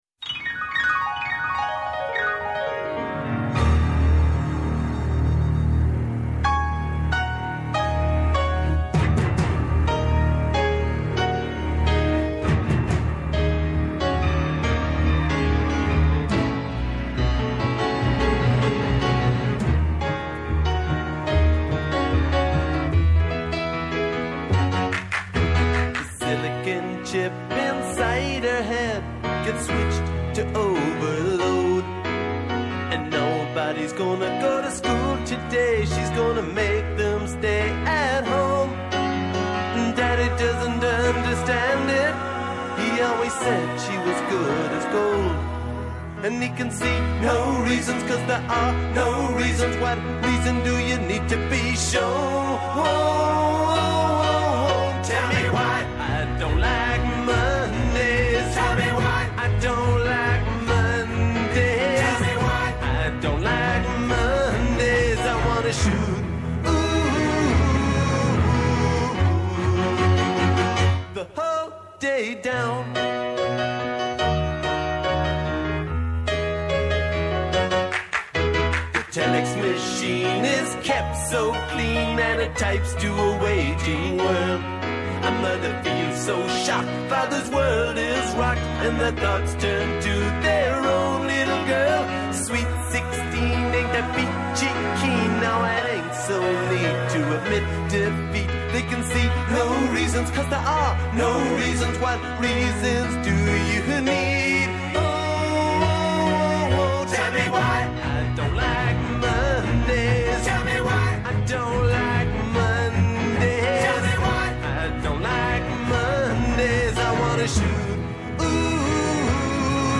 Πόση επικαιρότητα μπορεί να χωρέσει σε μια ώρα; Πόσα τραγούδια μπορούν να σε κάνουν να ταξιδέψεις;